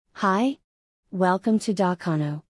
speech_1.mp3